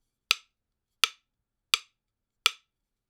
Acoustic drums Free sound effects and audio clips
drum sticks sound recorded in near field.wav
Recorded with a Steinberg Sterling Audio ST66 Tube, in a small apartment studio.
drum_sticks_sound_recorded_in_near_field_2cM.wav